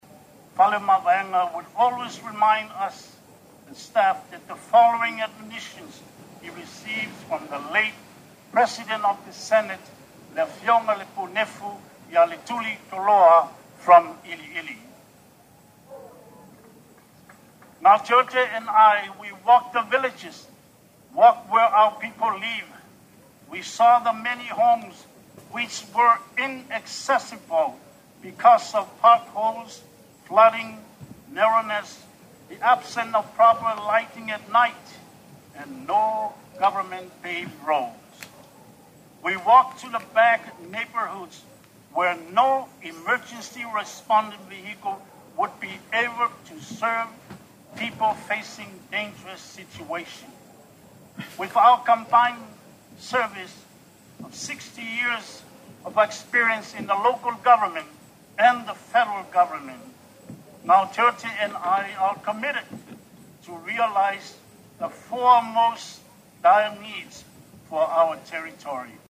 Before a packed crowd Saturday at the Veterans Memorial Stadium the gubernatorial team of Gaoteote Palaie Tofau and Faiivae Alex Godinet officially kicked off their quest to become the next governor and lt governor of American Samoa.
Lt Governor candidate Faiivae who addressed the crowd in English elaborated that the four cornerstones of their faletele, are quality health care, quality education, a vibrant economy and solid infrastructure.